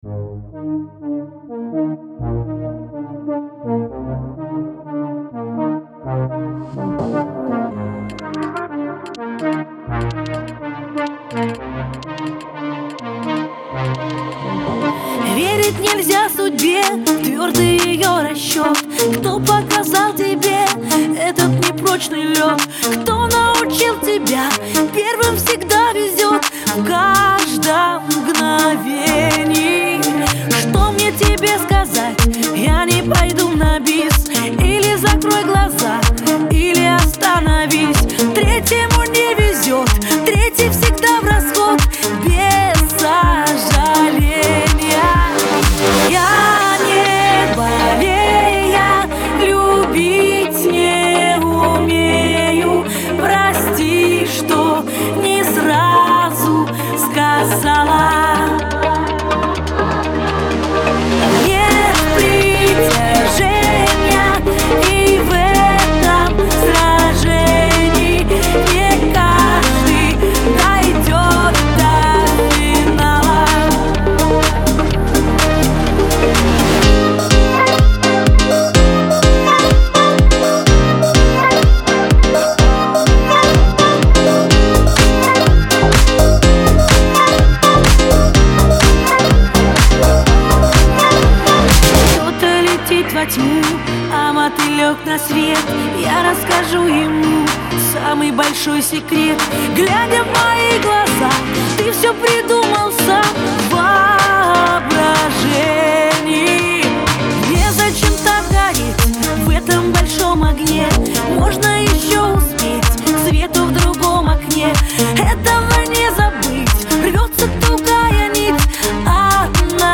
Категории: Русские песни, Поп.